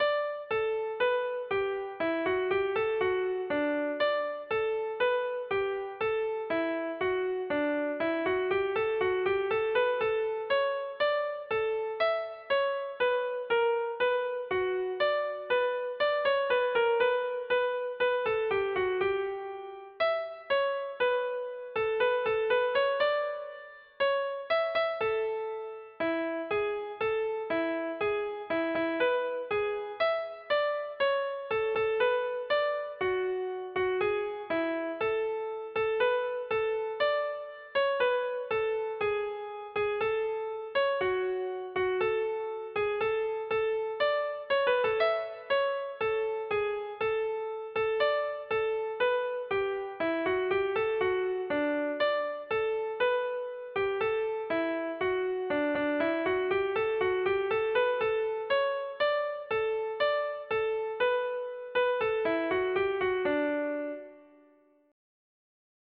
Music (Given or Suggested) Original tune